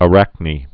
(ə-răknē)